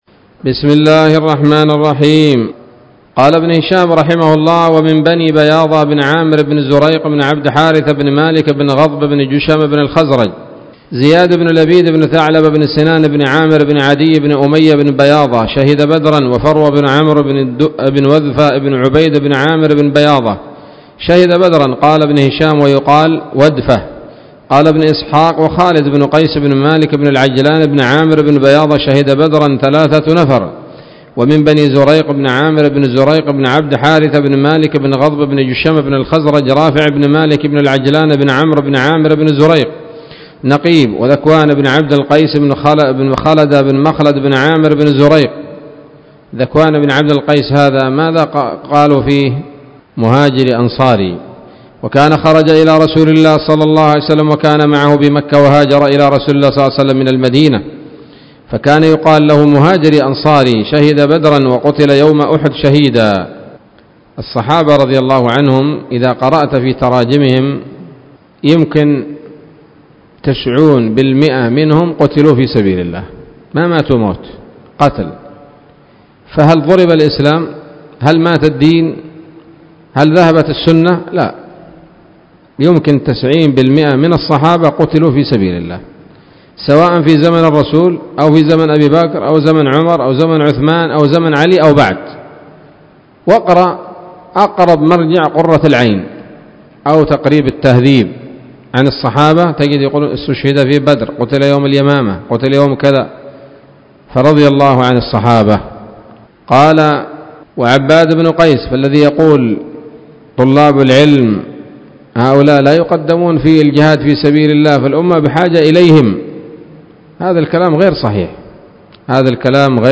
الدرس السابع والستون من التعليق على كتاب السيرة النبوية لابن هشام